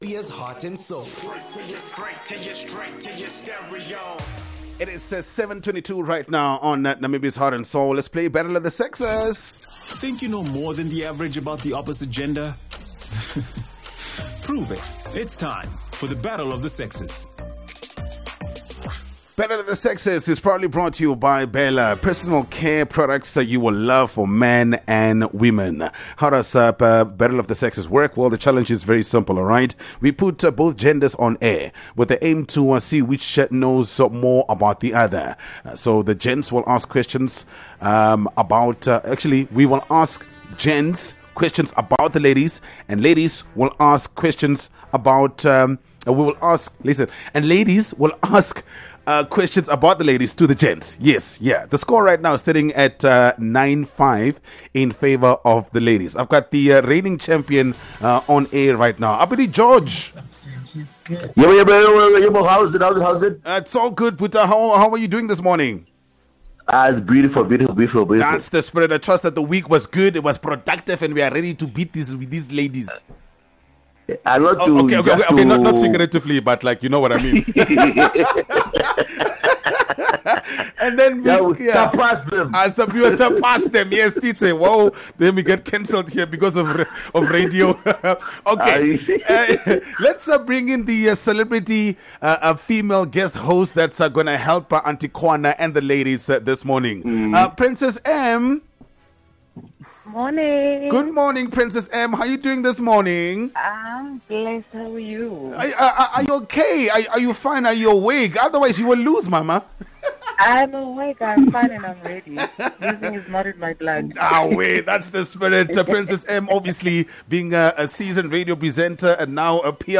Battle of the Sexes is probably the most dramatic game show on Namibian radio. With this gameshow, we have both genders on air with the aim to see which knows more about the other. So we ask the gents questions about the ladies and ladies…we ask questions about the gents!